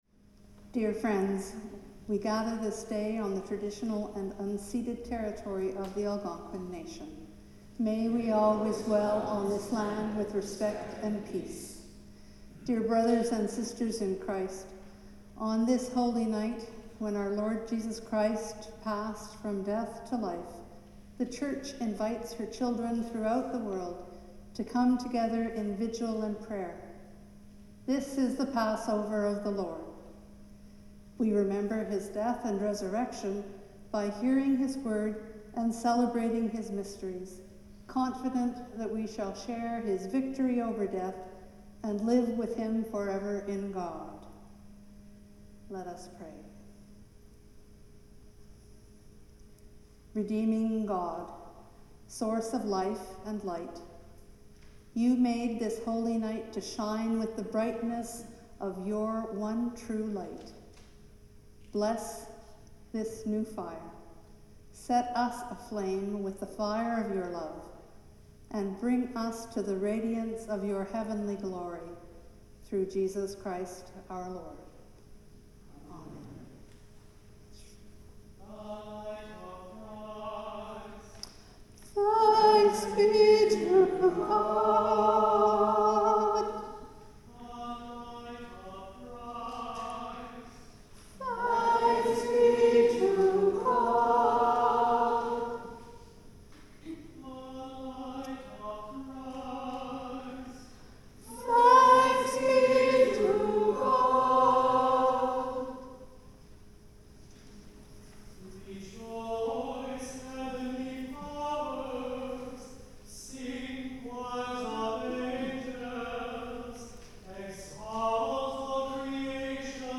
* This shorter recording includes excerpts from the Easter Vigil (a much longer service).